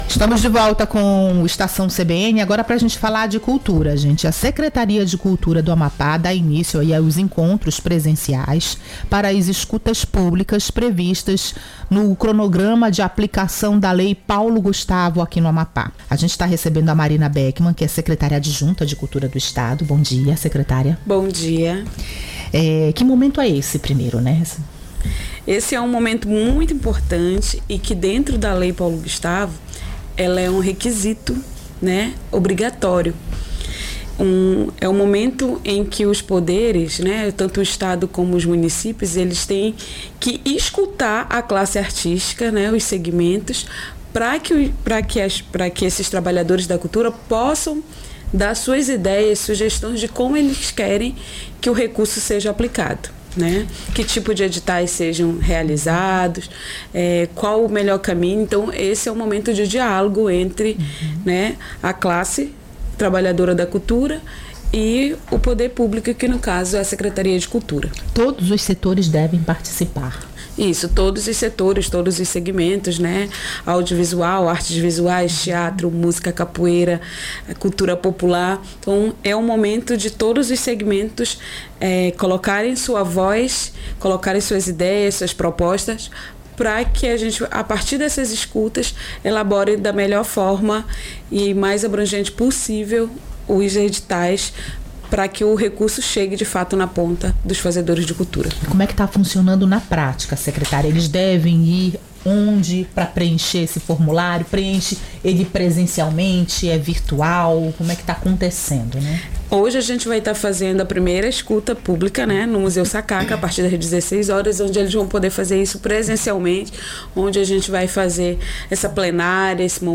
A secretária-adjunta de Cultura do estado,  Marina Beckman, esteve no Estação CBN desta segunda-feira (19) para explicar a importância desses encontros presenciais.